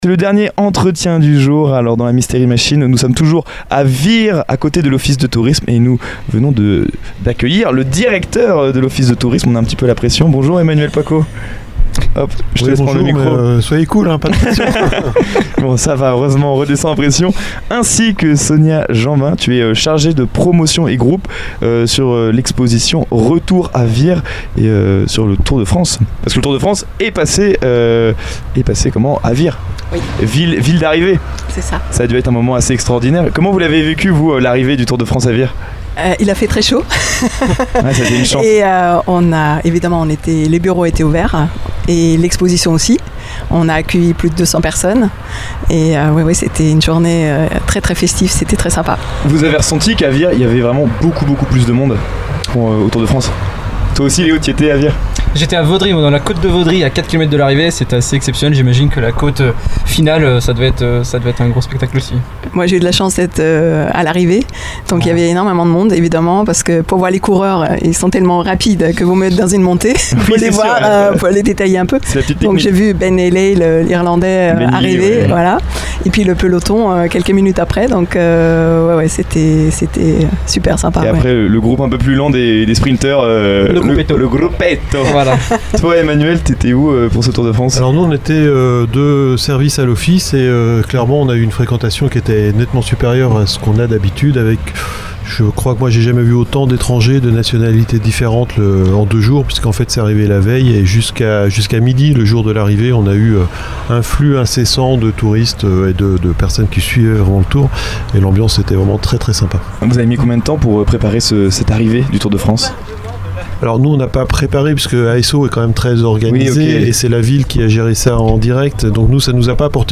Une interview complémentaire et passionnante qui met en lumière les coulisses de l’Office de tourisme et l’engagement de celles et ceux qui font vivre l’attractivité de Vire Normandie.